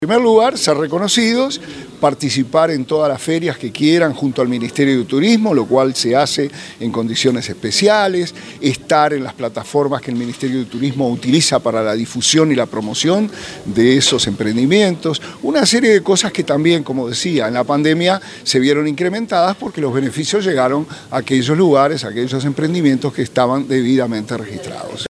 remo_monzeglio_-_subsecretario_de_turismo.mp3